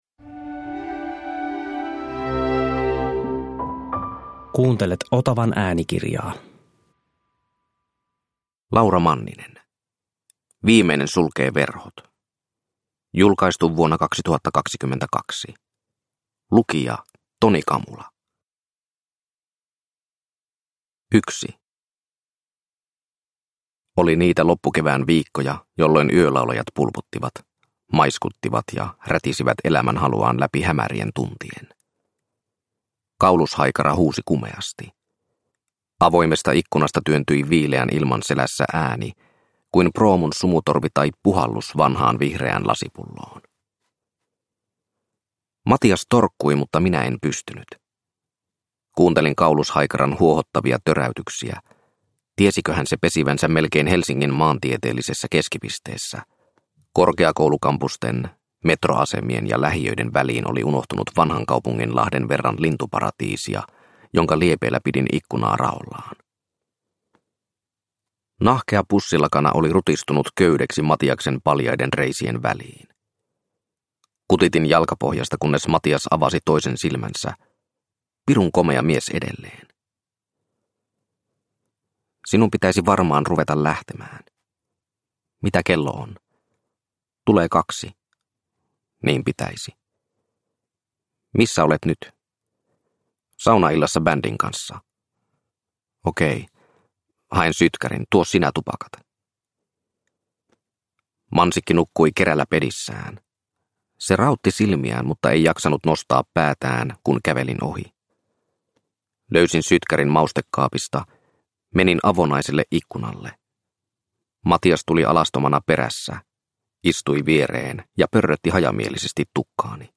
Viimeinen sulkee verhot – Ljudbok – Laddas ner